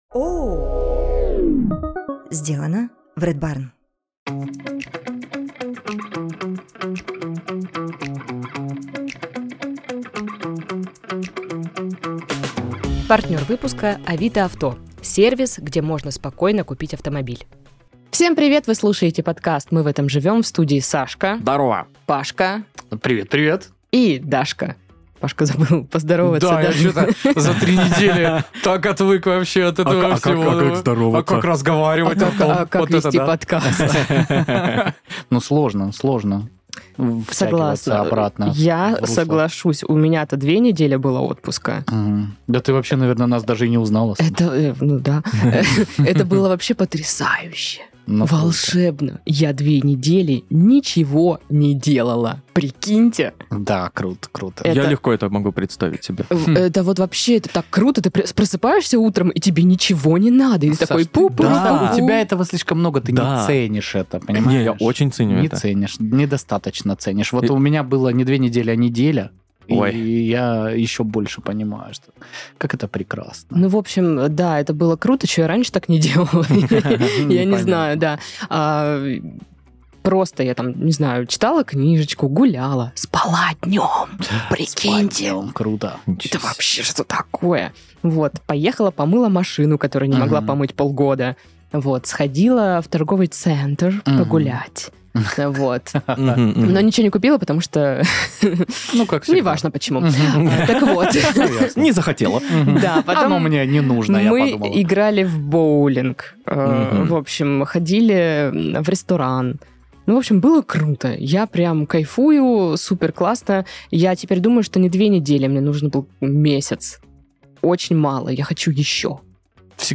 собираются в студии, чтобы обсудить забавные новости, смешные заголовки и повспоминать истории из жизни.